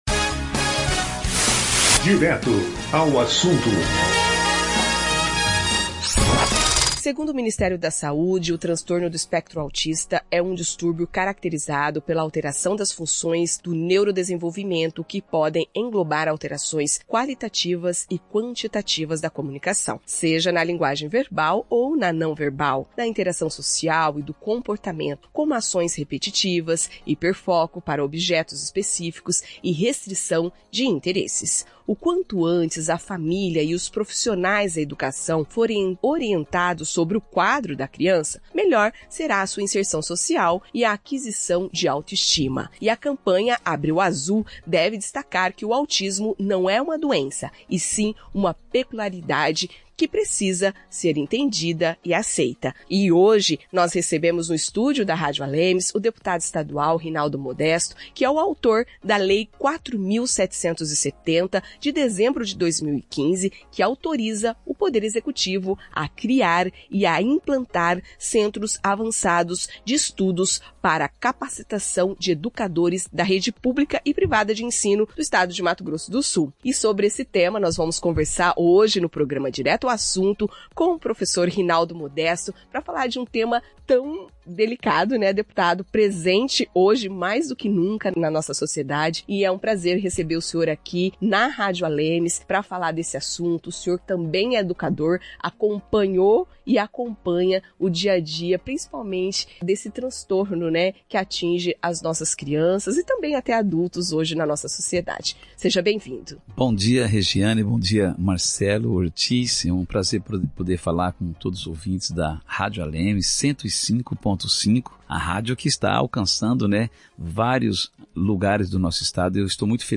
Abril é o mês de conscientização sobre o Transtorno do Espectro Autista (TEA), por isso o programa Direto ao Assunto da Rádio ALEMS traz uma entrevista com o deputado estadual professor Rinaldo Modesto, que é autor da lei que implanta centros avançados de estudos para capacitar educadores da rede pública e privada, trazendo como objetivo a inserção escolar de alunos diagnosticados com autismo.